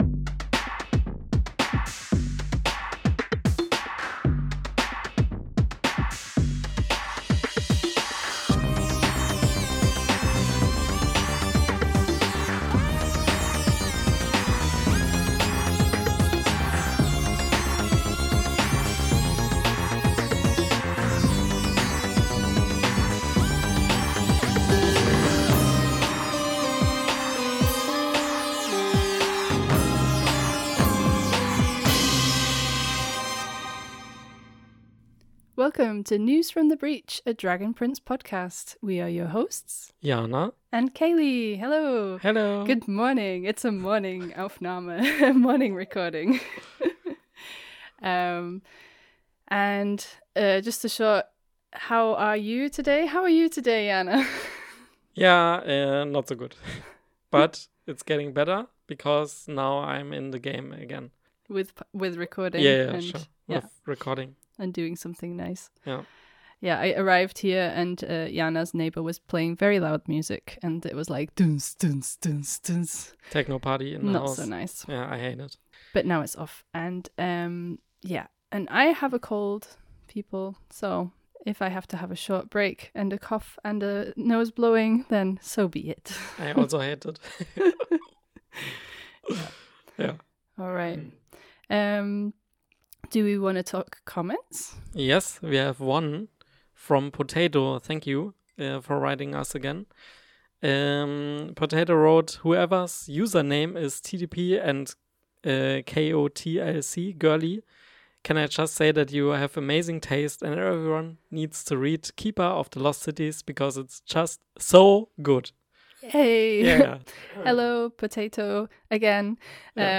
In this episode (on the lengthier side of the spectrum) we have lots of villain moments, try to calculate time and cough and sigh a lot, sorry!